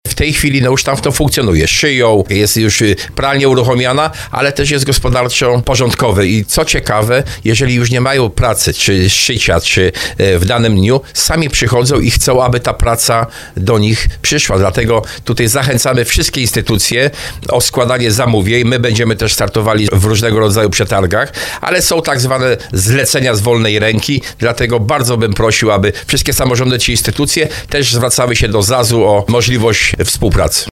Starosta powiatu tarnowskiego Jacek Hudyma, który był gościem programu Słowo za Słowo zwrócił uwagę na duże zaangażowanie osób, które podjęły pracę w ZAZ-ie.